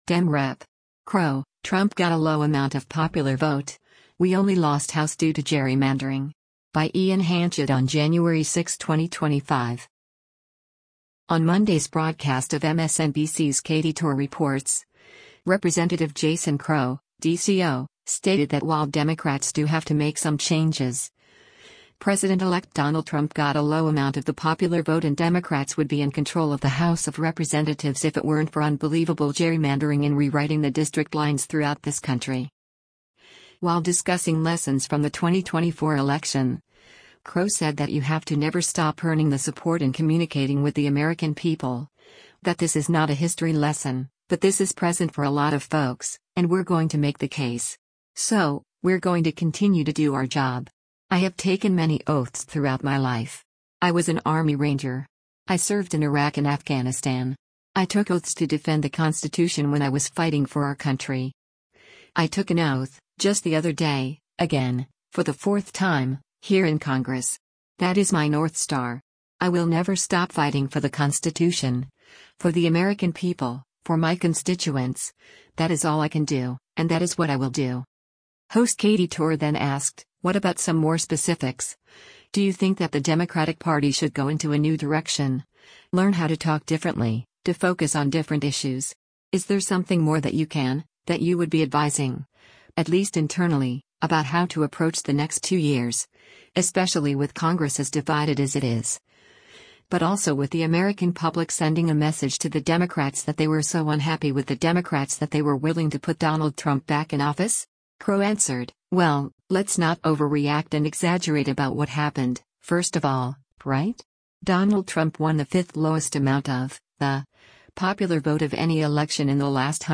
On Monday’s broadcast of MSNBC’s “Katy Tur Reports,” Rep. Jason Crow (D-CO) stated that while Democrats do have to make some changes, President-Elect Donald Trump got a low amount of the popular vote and “Democrats would be in control of the House of Representatives if it weren’t for unbelievable gerrymandering in rewriting the district lines throughout this country.”